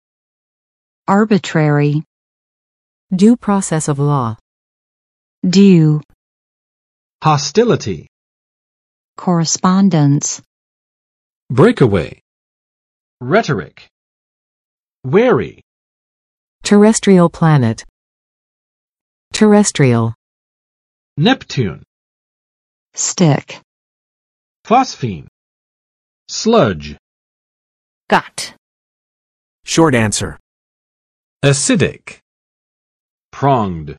[ˋɑrbə͵trɛrɪ] adj. 随心所欲的; 反复无常的，任性多变的